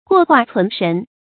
過化存神 注音： ㄍㄨㄛˋ ㄏㄨㄚˋ ㄘㄨㄣˊ ㄕㄣˊ 讀音讀法： 意思解釋： 過：經過；存：保存，具有。